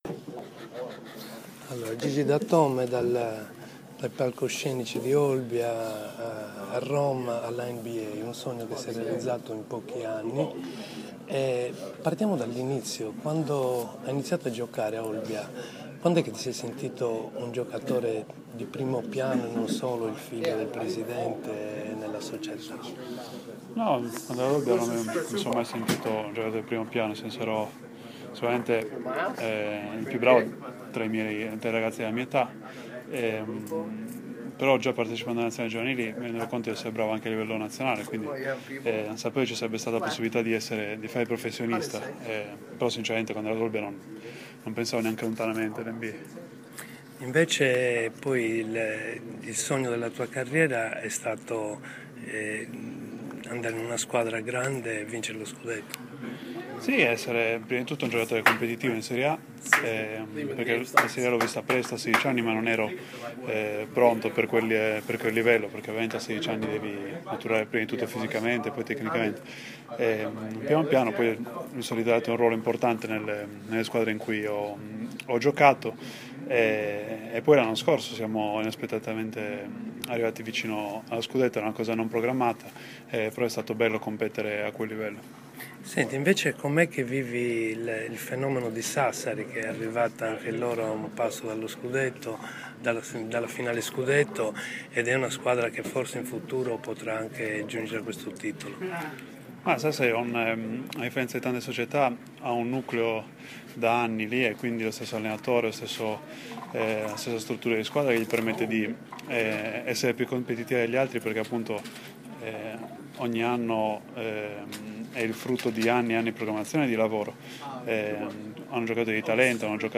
Ho atteso a New York un mese che arrivasse l’ok dalla NBA per poterlo incontrare negli spogliatoi del Madison Square Garden prima del suo incontro coi Knicks e lui, con gentilezza e professionalità mi ha parlato di questo suo momento, della sua vita, la sua carriera, la sua sardità il suo dolore per l’alluvione a Olbia.